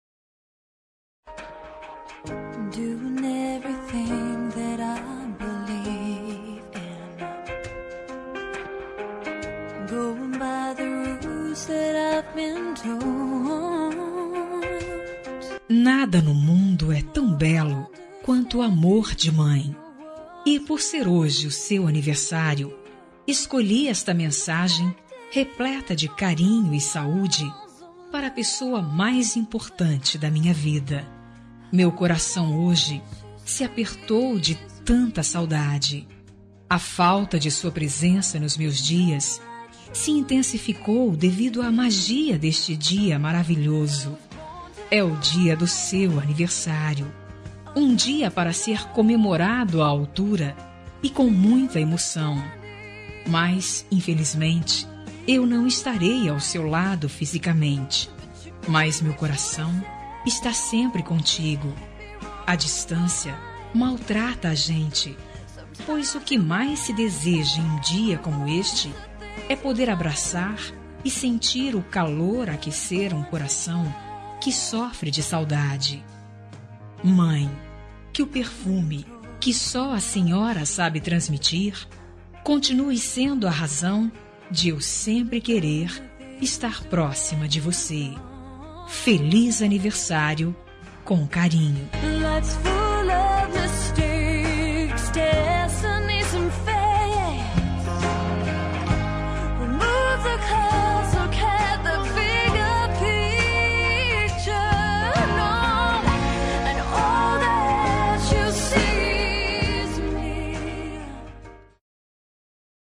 Aniversário de Mãe – Voz Feminina – Cód: 035382 – Distante